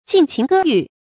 禁情割欲 jìn qíng gē yù
禁情割欲发音
成语注音 ㄐㄧㄣˋ ㄑㄧㄥˊ ㄍㄜ ㄧㄩˋ